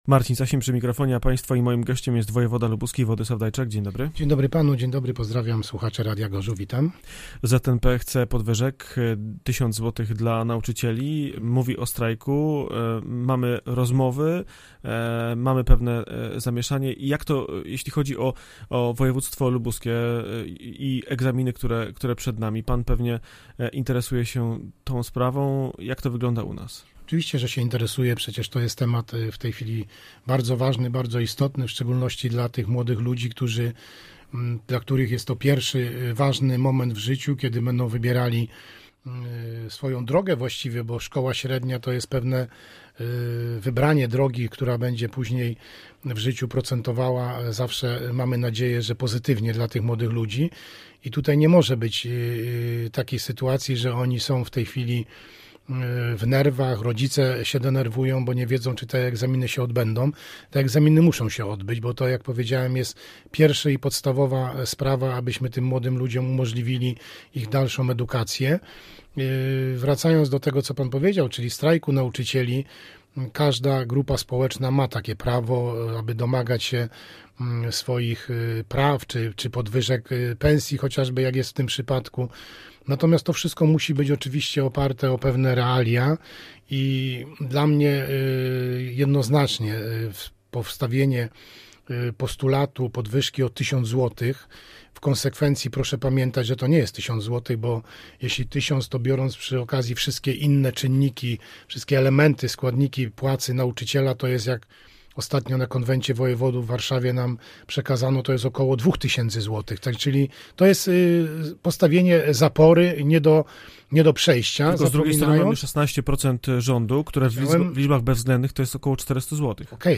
poranna-rozmowa-wojewoda-wladyslaw-dajczak.mp3